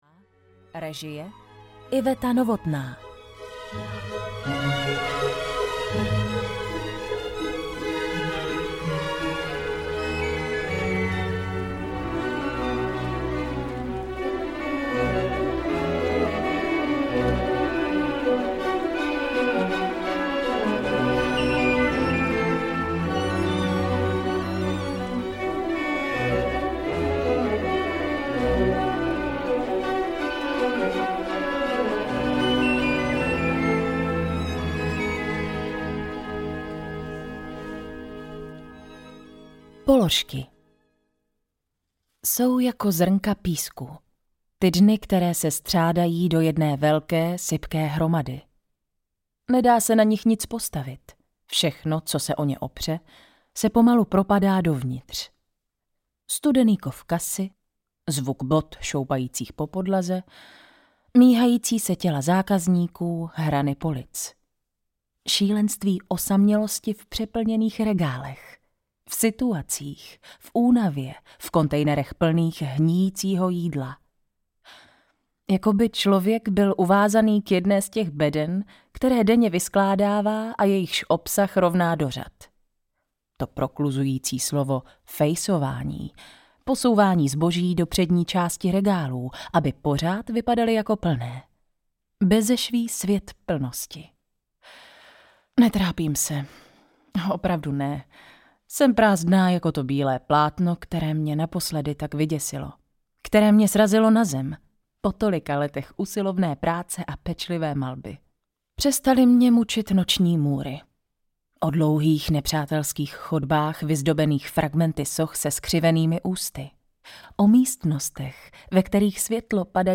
Směna audiokniha
Ukázka z knihy